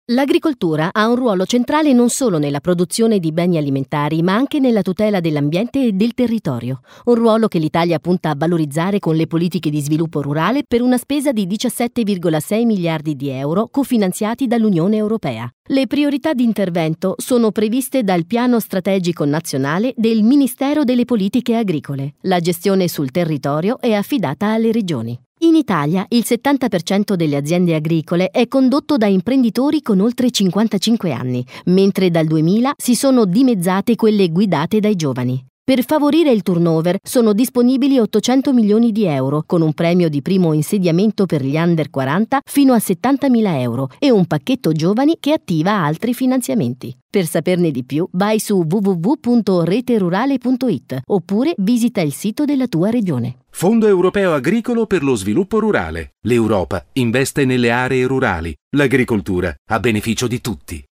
Messaggi radiofonici